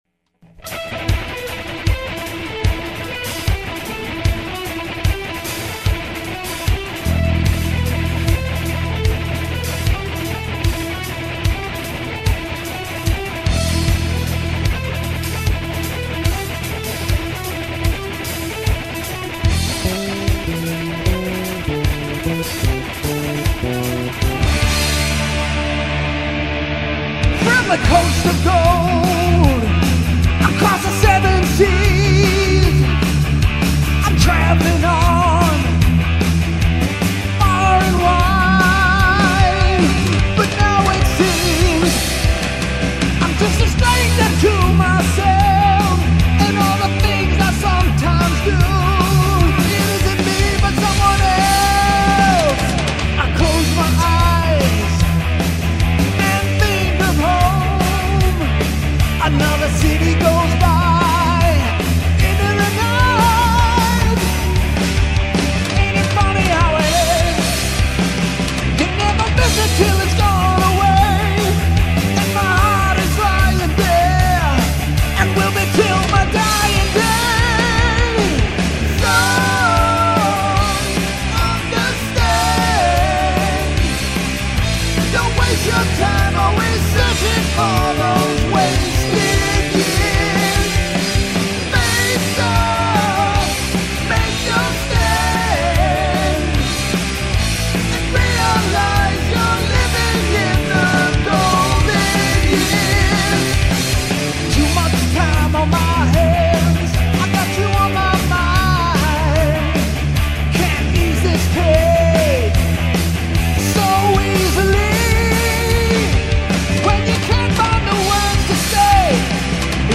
karaoke tune
really pumping out that range.